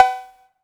Perc [ Pick Up The Phone ].wav